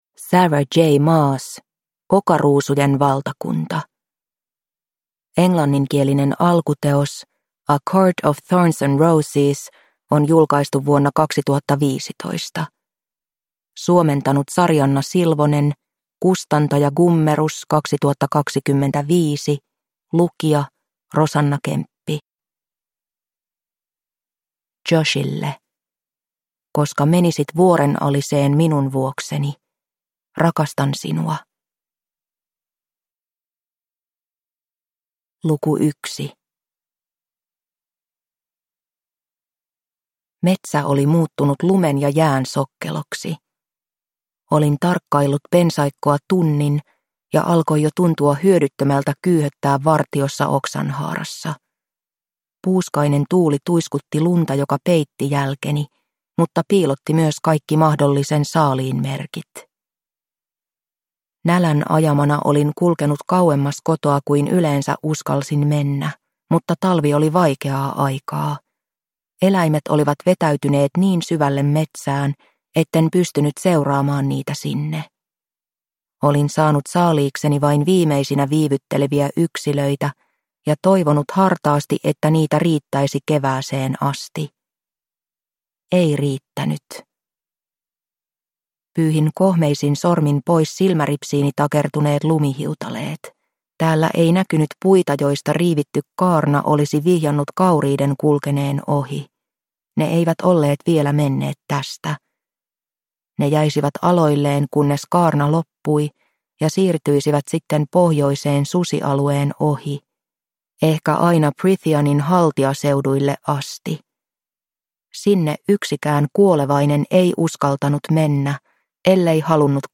Okaruusujen valtakunta – Ljudbok